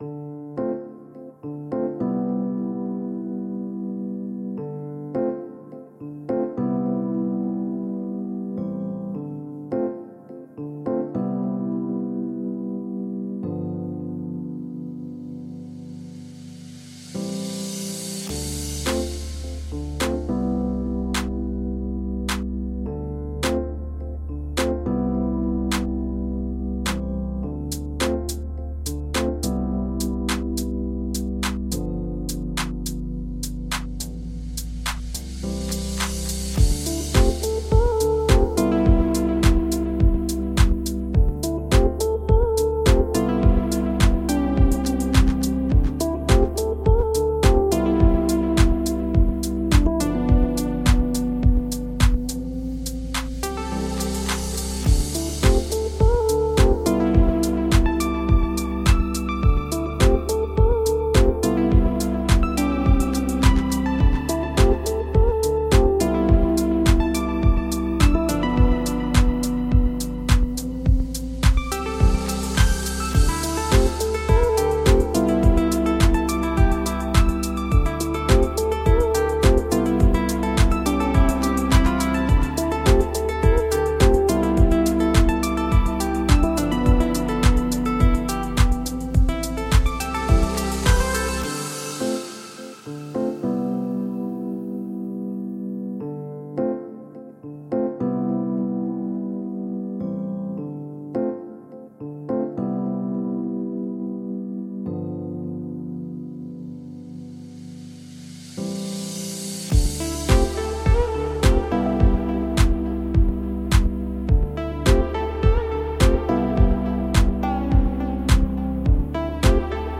Фоновая музыка для обзора предмета